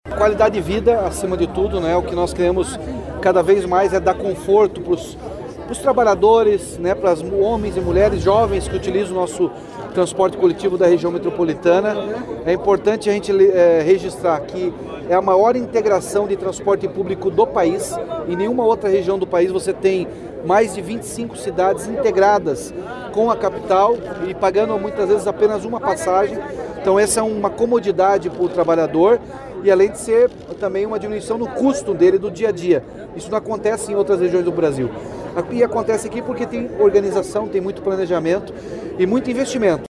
O governador Ratinho Junior disse que a entrega dos veículos se soma a outras ações que já estão em andamento na Região Metropolitana de Curitiba, para melhorar a vida de quem se desloca entre a capital e as cidades vizinhas.